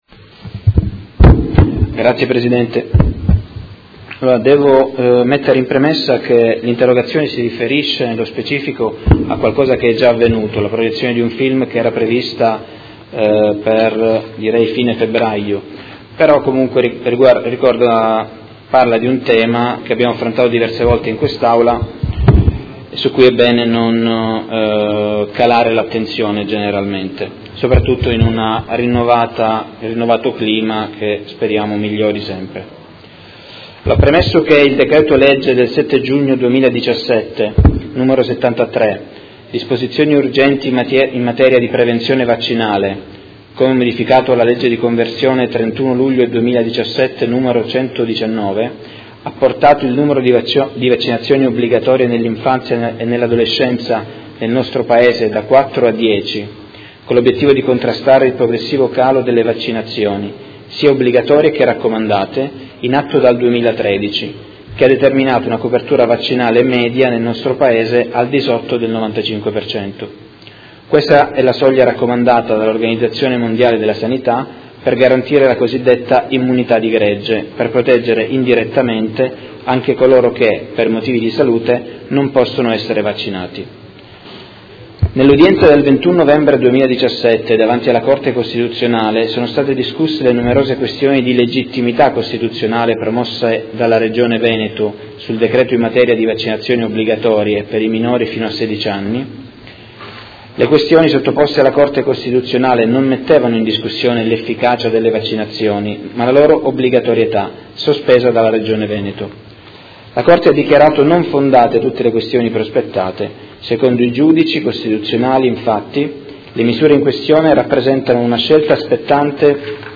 Seduta del 5/04/2018. Interrogazione del Consigliere Fasano (PD) avente per oggetto: Contrasto ad iniziative di disinformazione sulle vaccinazioni e sull’importanza della copertura vaccinale